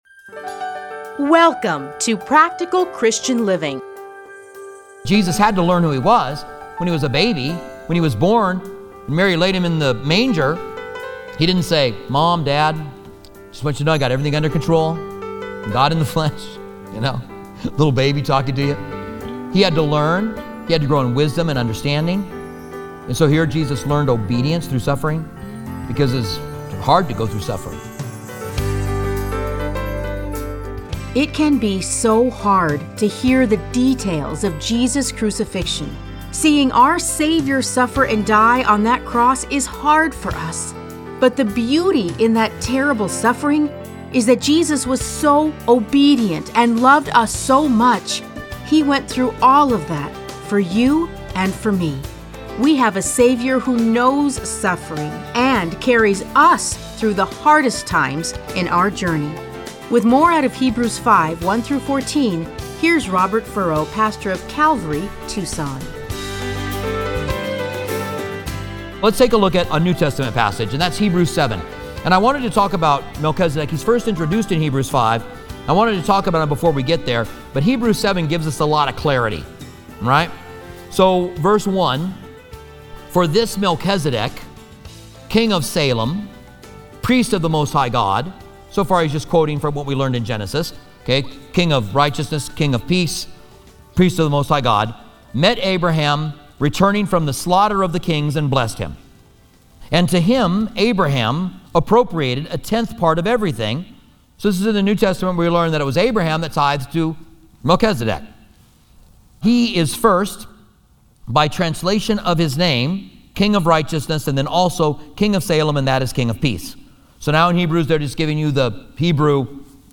Listen to a teaching from Hebrews 5:1-14.